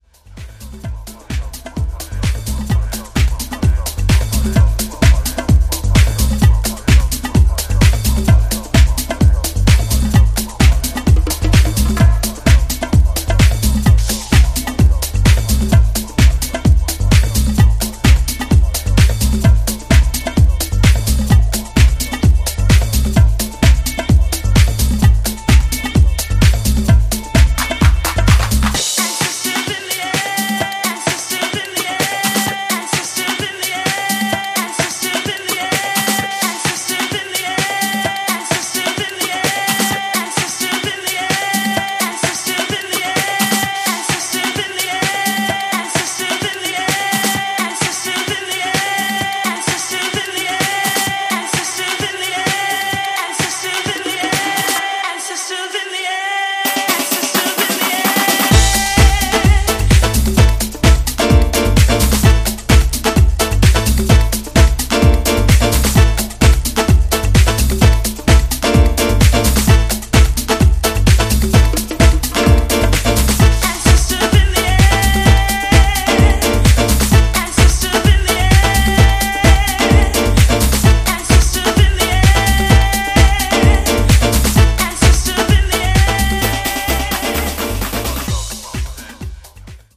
各曲フレッシュな勢いで溢れており、ピークタイムを気持ちよく沸かせてくれることでしょう！